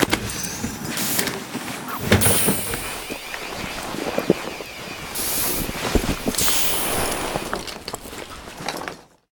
suitwear.ogg